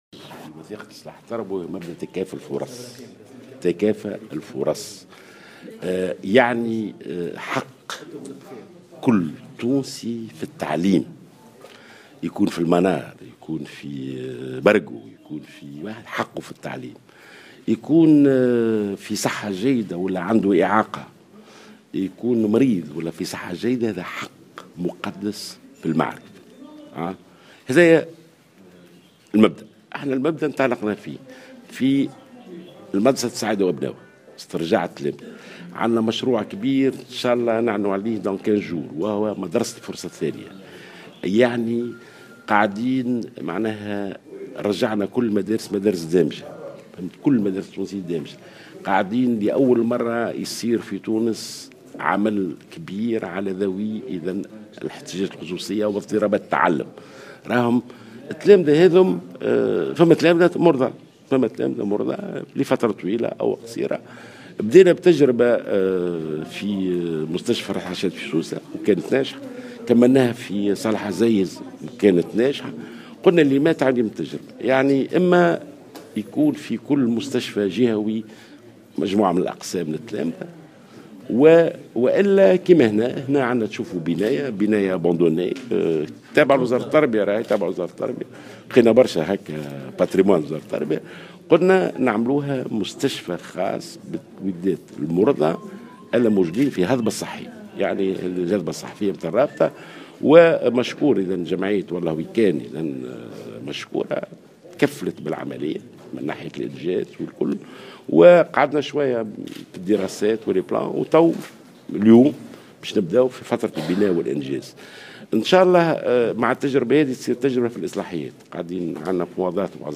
وجاءت تصريحاته على هامش إعطاء شارة انطلاق إنجاز مشروع مدرسة الهضبة الصحية بمستشفى شارل نيكول بالعاصمة التي ستمكن المرضى من التلاميذ المقيمين بهذه المؤسسة الاستشفائية من مواصلة دراستهم في ظروف عادية. وأفاد وزير التربية ناجي جلول في تصريح اعلامي ان هذا المشروع من المنتظر ان يكون جاهزا في ظرف 6 أشهر وبطاقة استيعاب تبلغ 145 تلميذا.